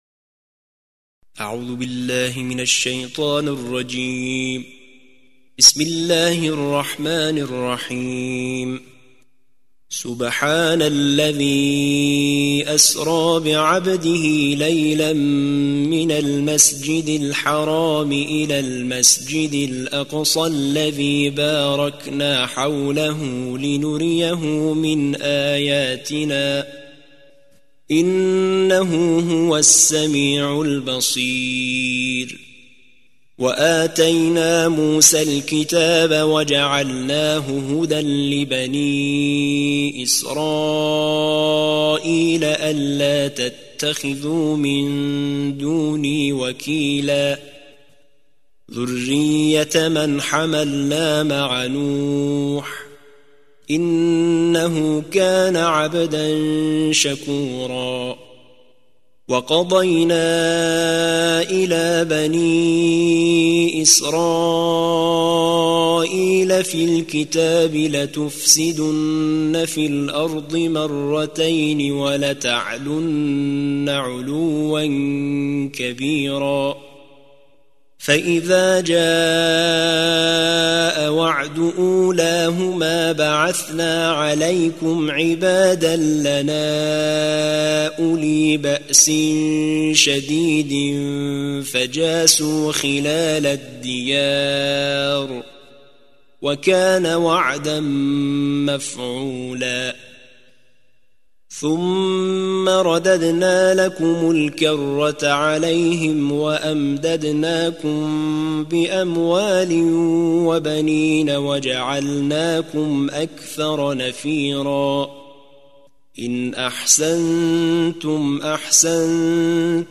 ترتیل جزء پانزدهم قرآن